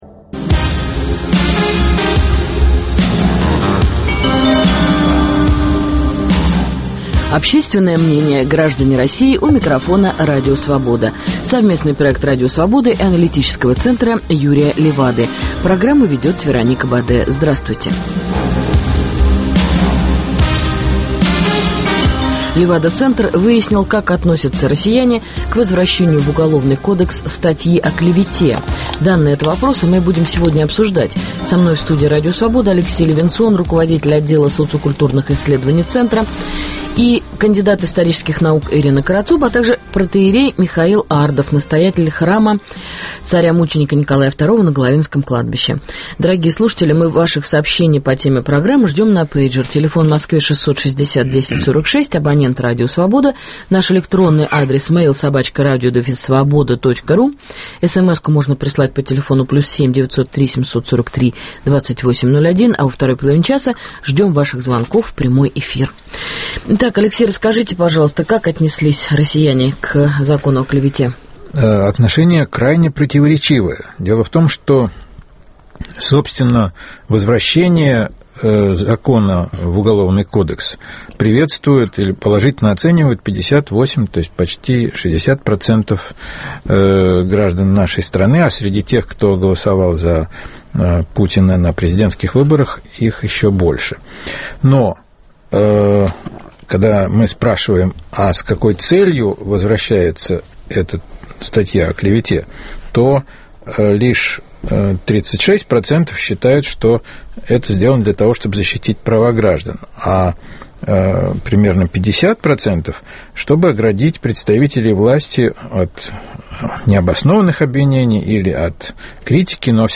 Аналитический Центр Юрия Левады исследовал отношение россиян к «закону о клевете». Данные этого исследования в эфире обсуждают социолог